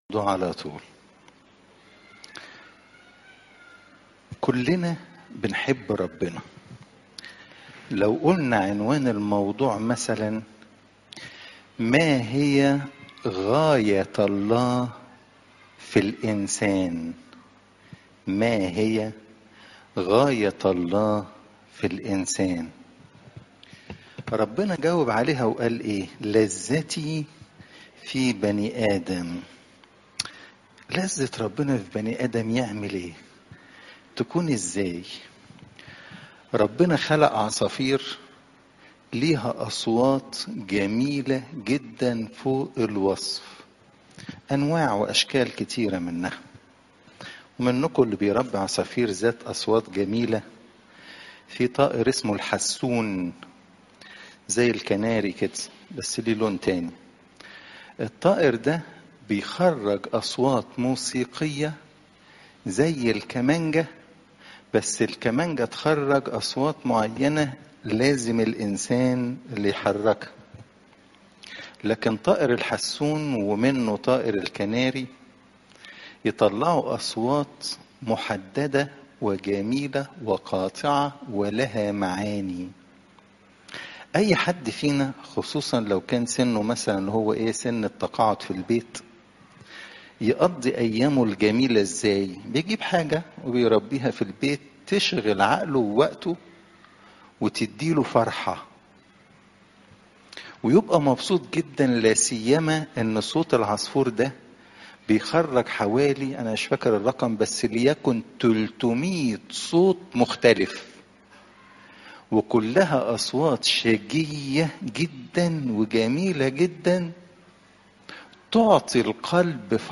إجتماع السيدات